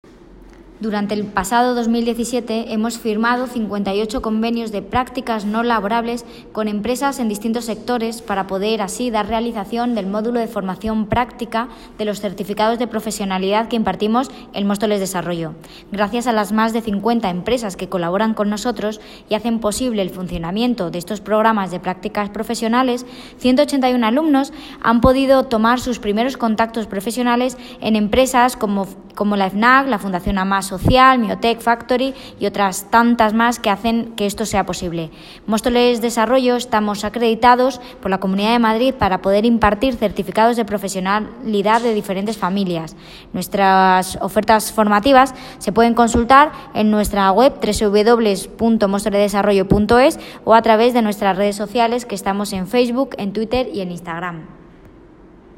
Audio - Jessica Antolín (Concejala Desarrollo Económico, Empleo y Nuevas Tecnologías) Sobre Programa Prácticas